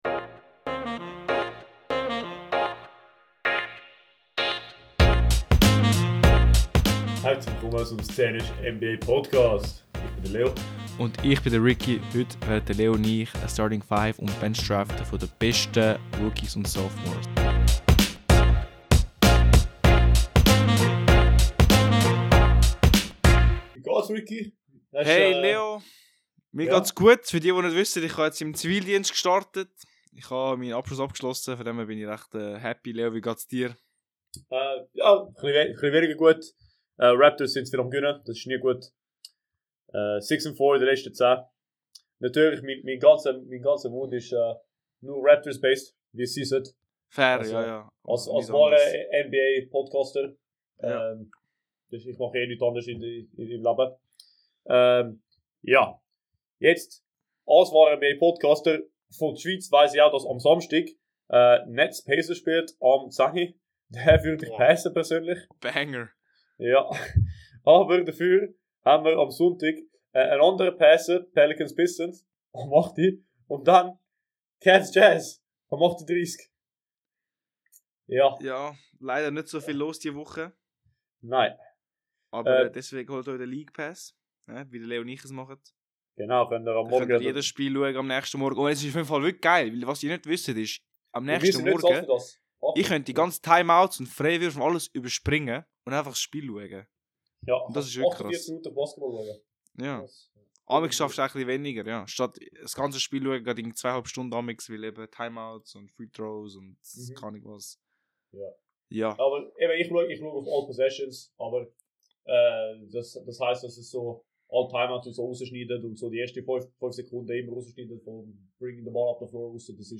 Zwei Jungs us Züri, zwei Mikros, nur NBA.